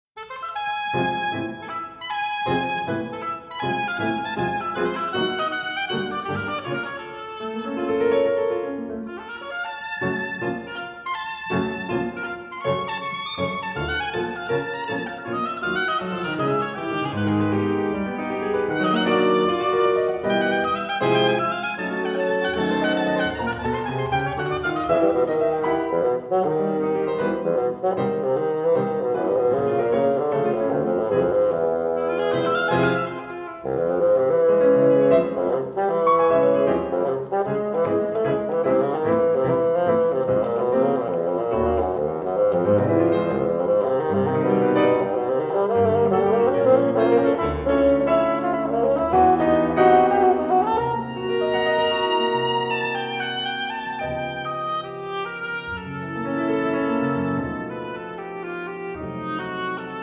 bassoon
Oboe